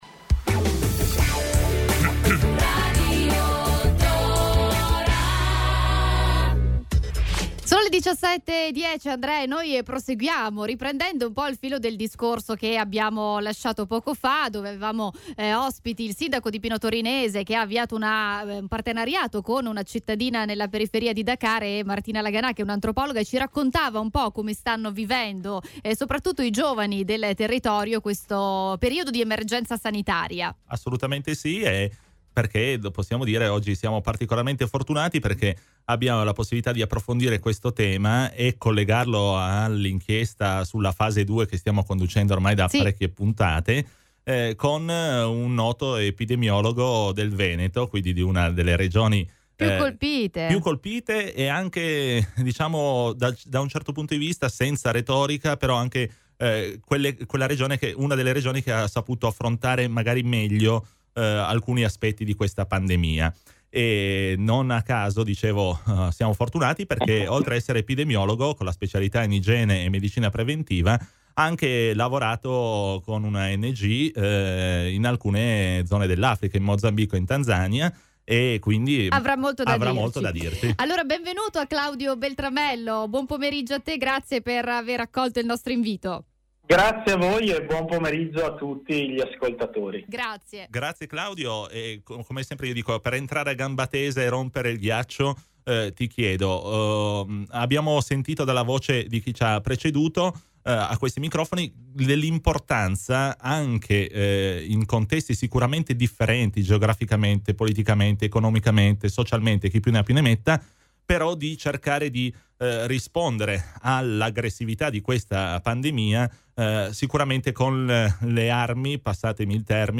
Home Intervista Prevenzione e solidarietà in e dall’Africa 7 Maggio 2020 Mai come in questo momento è importante non lasciare indietro nessuno e continuare a fare prevenzione.